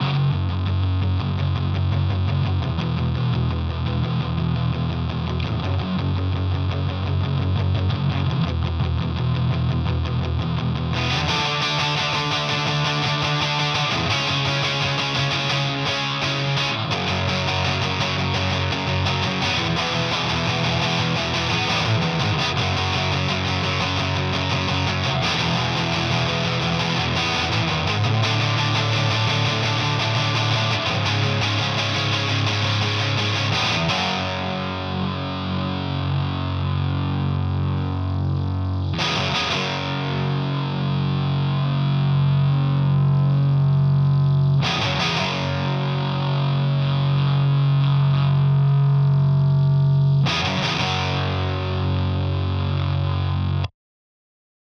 Bluecat is the left channel. LePou is the right
classic clean - this was the hardest to find a matching LP equivalent
Cab sims are all NaDir.
The big thing I'm noticing is that the Bluecat sims are more "squashed" overall. There is less dynamic response to the incoming guitar tone, and the noise floor is a lot higher.